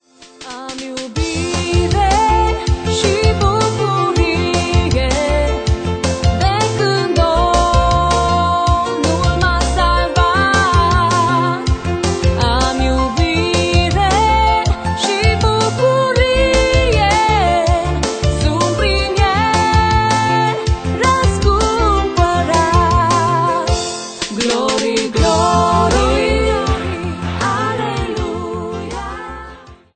Personalitate, forta, dinamism, energie si originalitate.